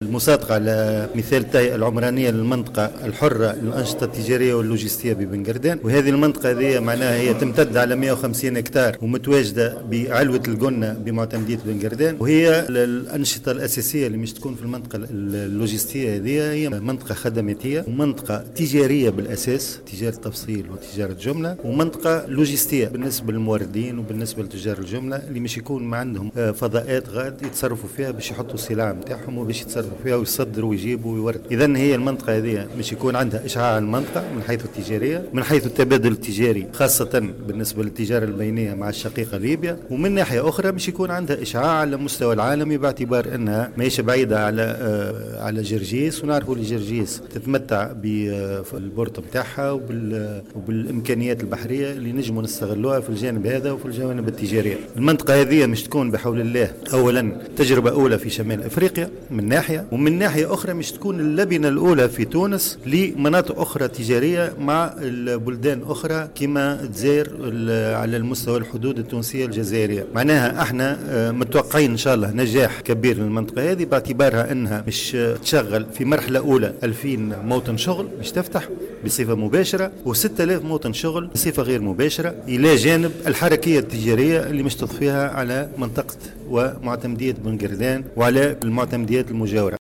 من جانبه أكد الرئيس المدير العام للديوان التونسي للتجارة الياس بن عامر، في تصريح لمراسلنا، ان هذه المنطقة التي تعتبر الاولى من نوعها بشمال افريقيا ستوفر 2000 موطن شغل بصفة قارة وحوالي 6000 موطن شغل غير قار.